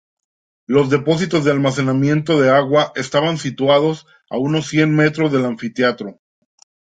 me‧tros
/ˈmetɾos/